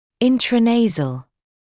[ˌɪntrə’neɪzl][ˌинтрэ’нэйзл]внутриносовой